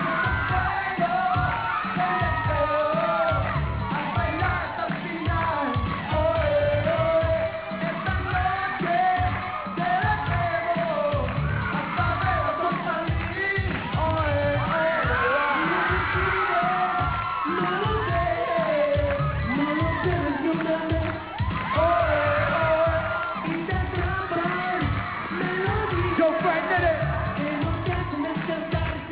Live Clips
These are various clips from different performances